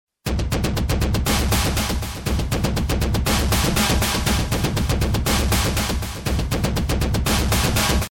“Ping pong” delay
Delay subdivided and bouncing between the left and right channel.
Ping Pong Delay
Listen to how the sound bounces left and right as part of the delay effect.
hfx5_7_Ping_Pong_Delay.mp3